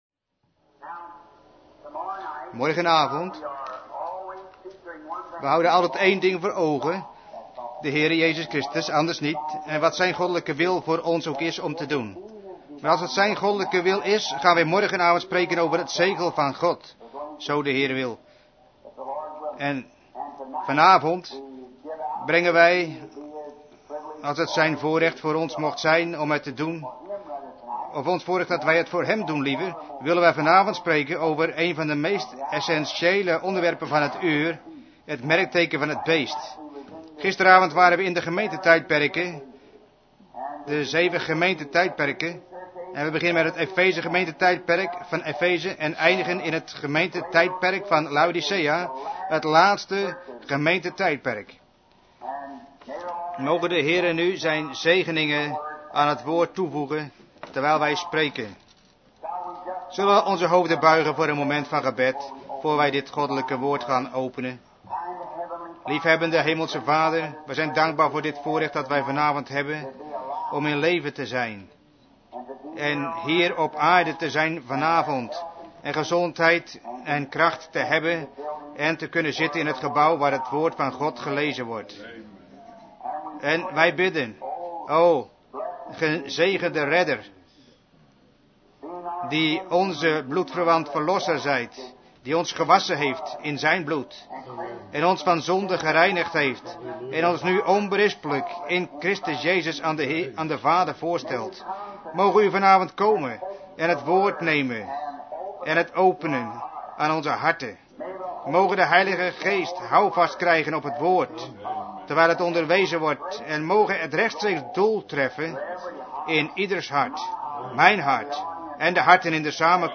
Vertaalde prediking "The mark of the beast" door William Marrion Branham te Branham Tabernacle, Jeffersonville, Indiana, USA, op donderdag 13 mei 1954